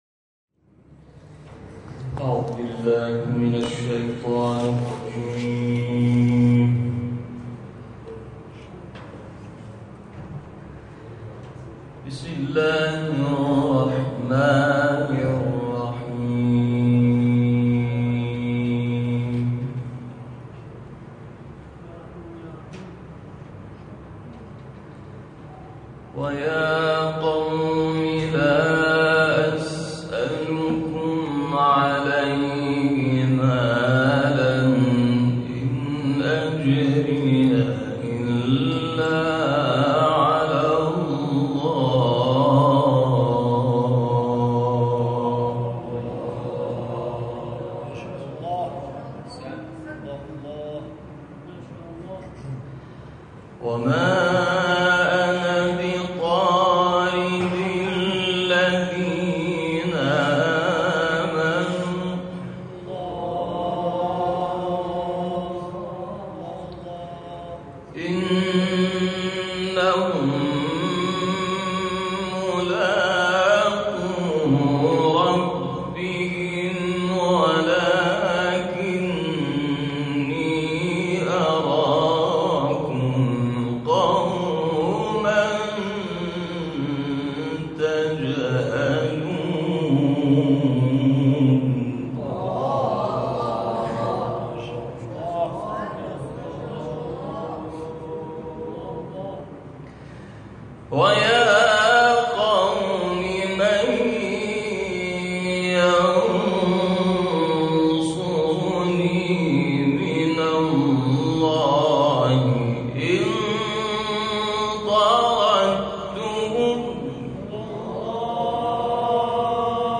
این تلاوت کوتاه و فنی روز گذشته 8 دی ماه اجرا شده است.